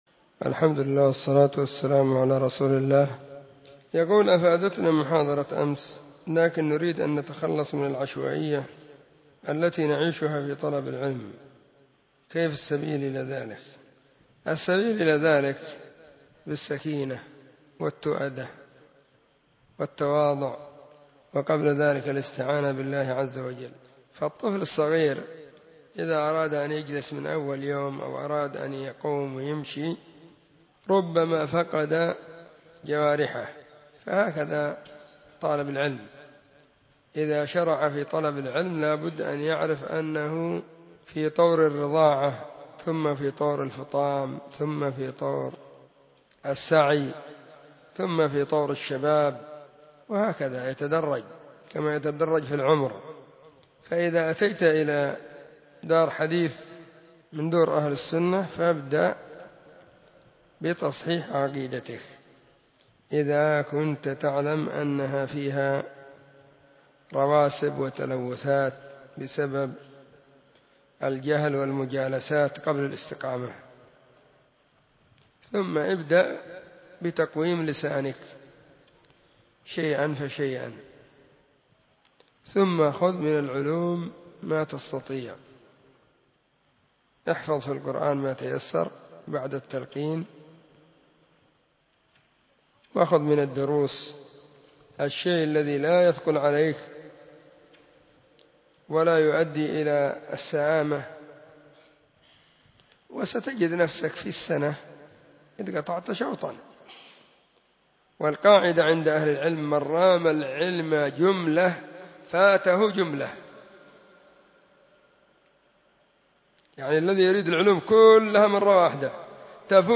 🔸🔹 سلسلة الفتاوى الصوتية المفردة 🔸🔹